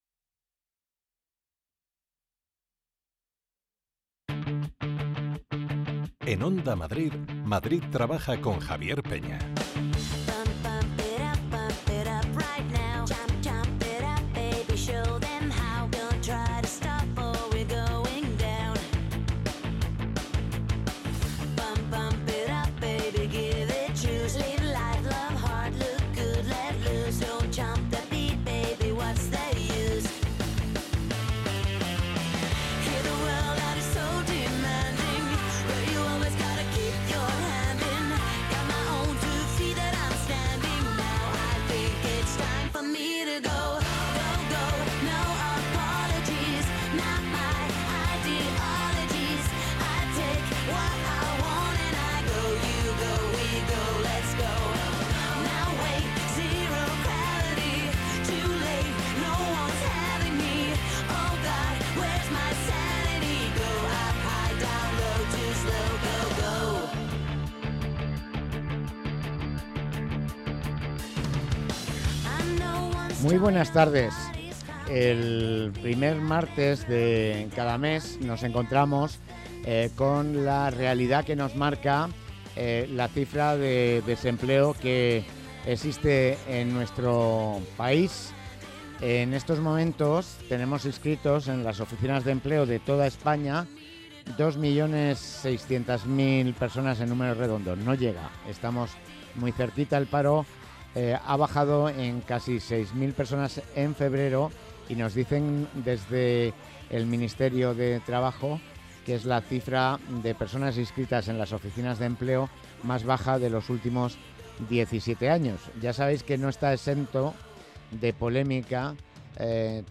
Ofertas de empleo en la Comunidad de Madrid del 4 de marzo de 2025 Hoy hemos recibido en nuestro estudio de Onda Madrid a dos emprendedores del sector de la hostelería que participan en el II Hub Origen de Hostelería Madrid y la Comunidad de Madrid.